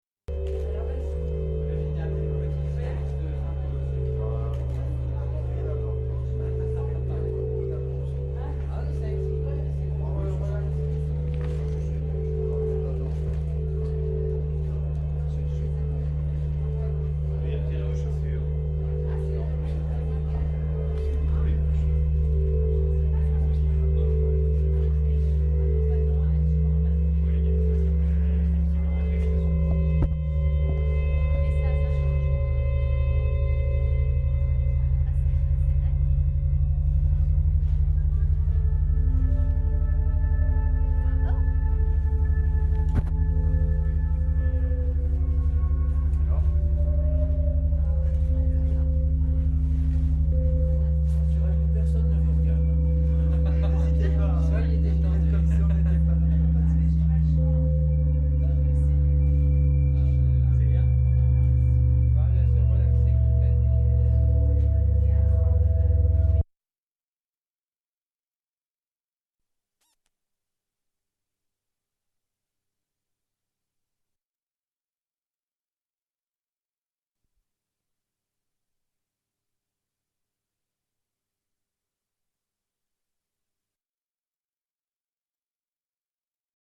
Maison Folie de Wazemmes
installation sonore